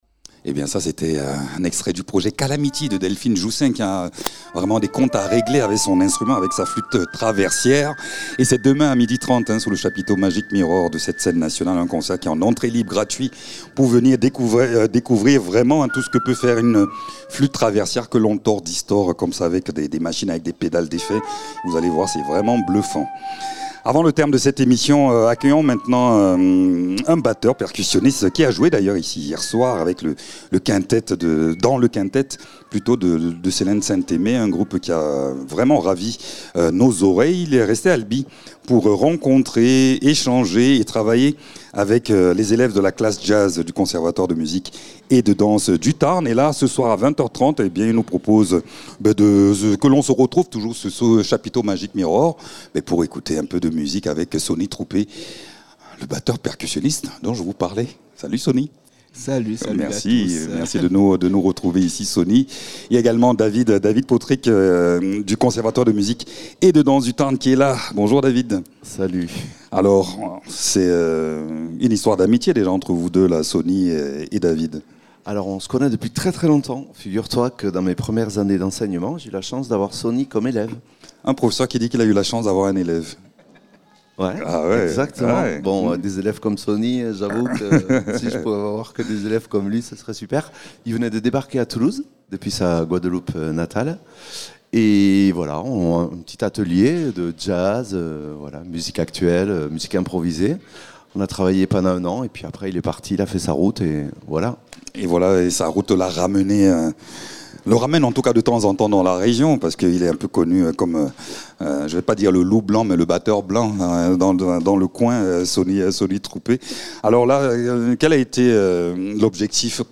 Une collaboration est en place entre le Albi Jazz Festival et le CMDT (Conservatoire de Musique et de Danse du Tarn) : des artistes de la programmation du festival viennent animer des journées d’ateliers avec les élèves. Sonny Troupé, batteur et percussionniste, est venu raconter au micro la journée pédagogique qu’il a passé avec elles et eux.